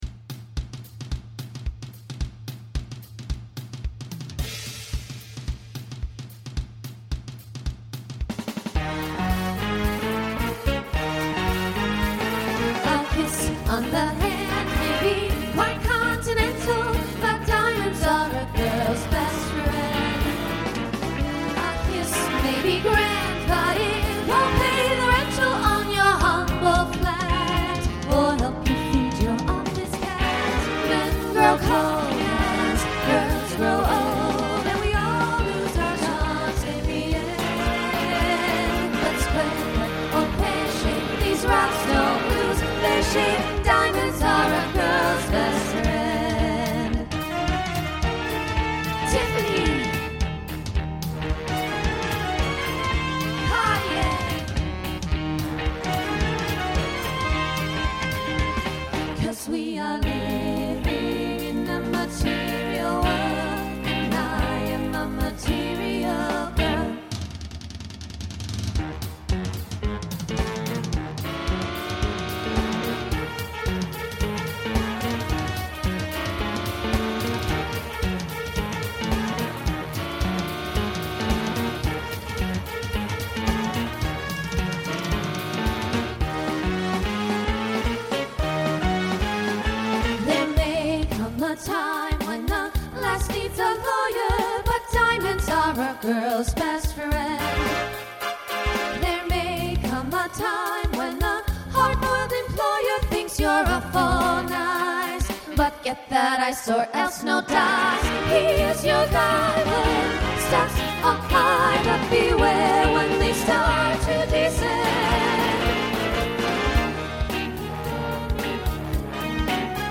Mid-tempo